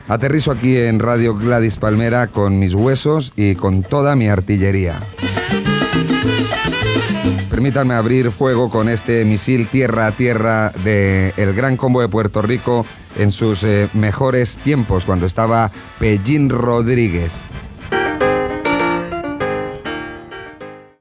Llatina
Presentació d'un tema.
Musical